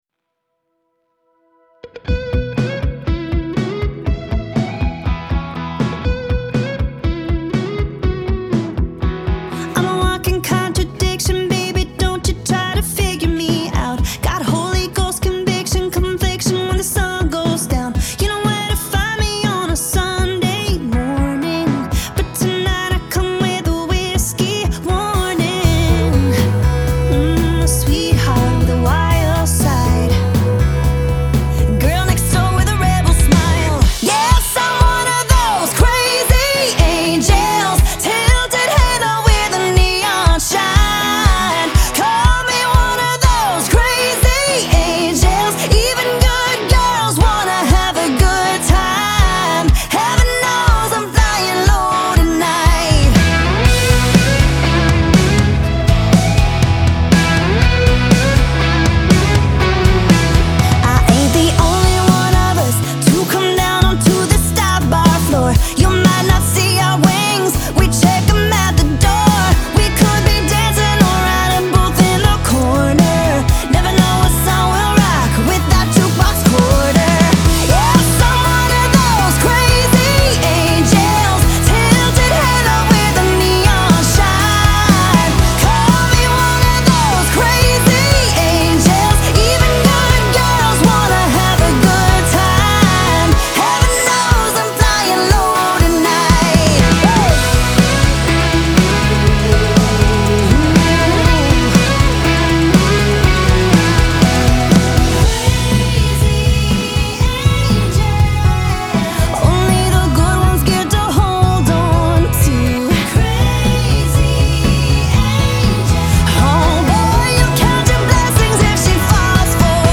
Genre : Country, Folk, Blues